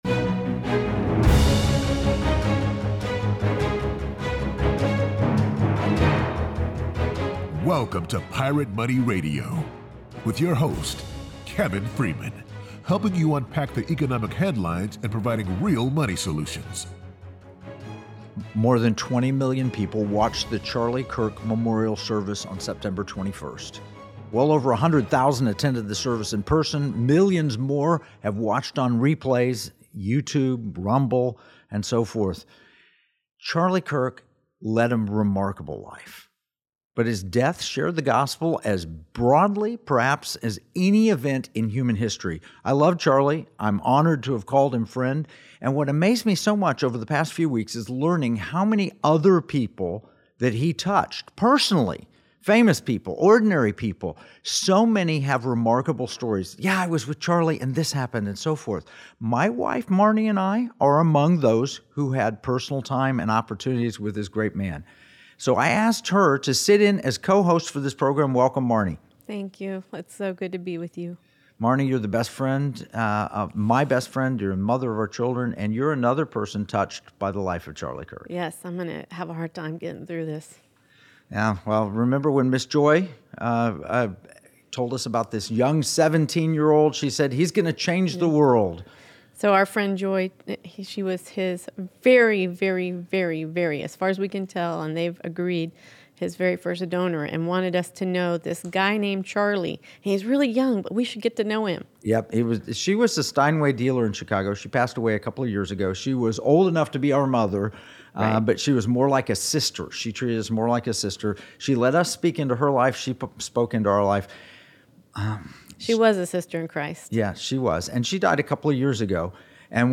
They revisit Kirk’s sharp insights on liberty, culture, economics, and the spiritual roots of national renewal, featuring clips on moral foundations, socialism, and the path to restoring America.